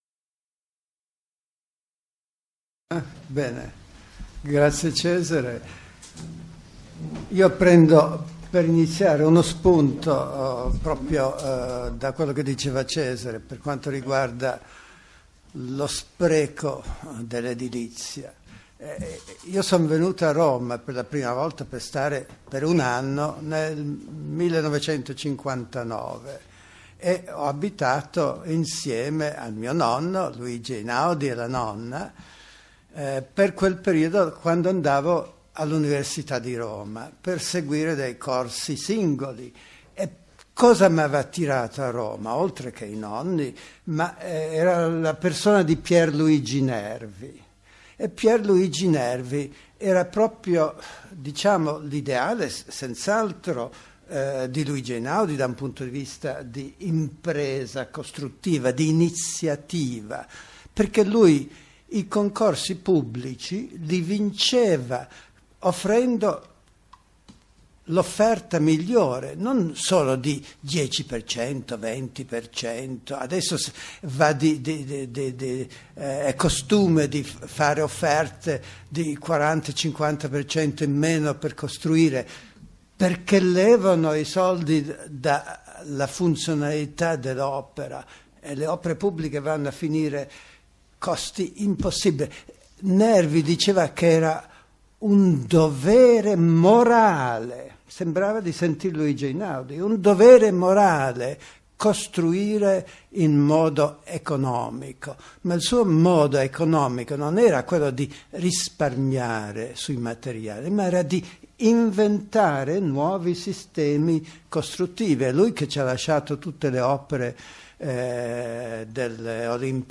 Dibattito